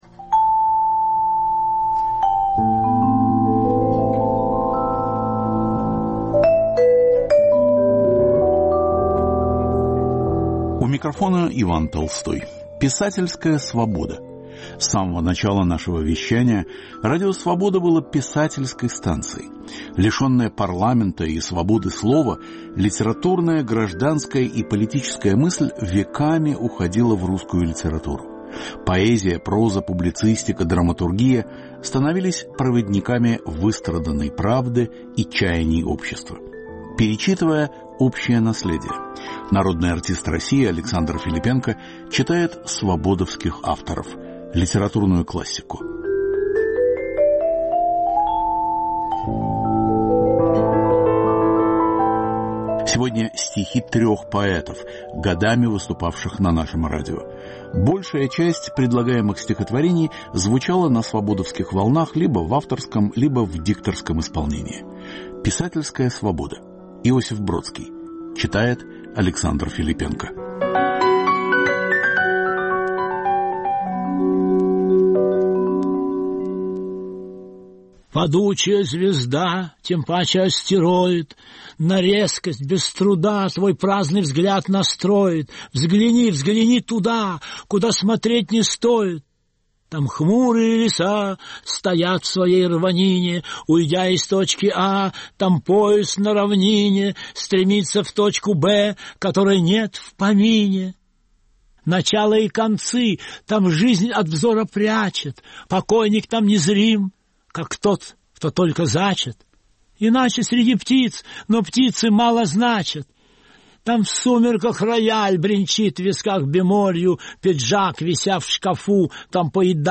Народный артист России Александр Филиппенко читает стихи свободовских авторов
Цикл литературных подкастов мы заканчиваем поэтическим чтением.